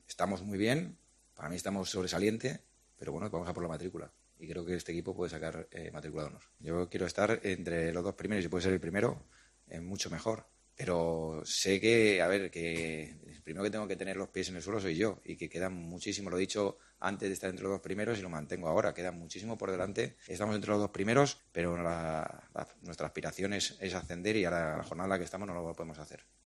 "Yo sí pienso que todavía podemos seguir mejorando. Estamos muy bien, para mí de sobresaliente, pero vamos a por matricula y creo que este equipo puede sacar matrícula de honor", dijo en la rueda de prensa previa al partido.